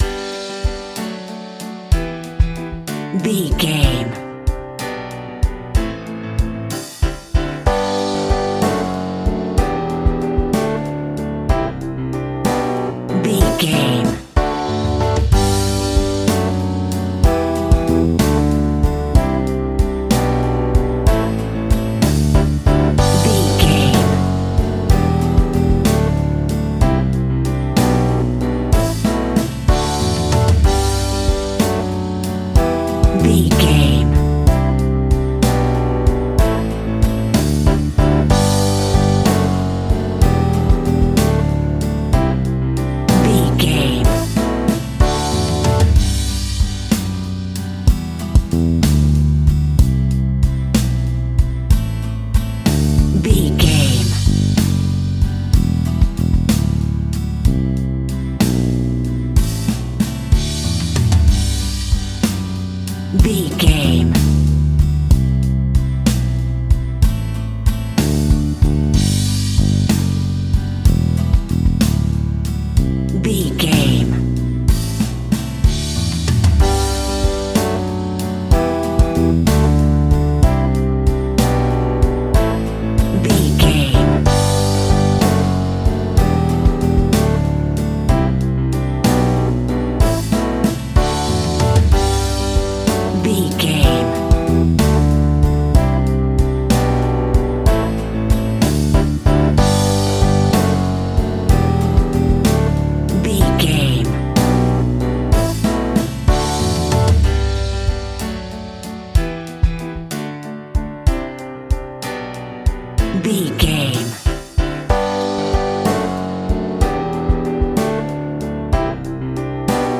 Uplifting
Ionian/Major
A♭
pop rock
indie pop
fun
energetic
acoustic guitars
drums
bass guitar
electric guitar
piano
organ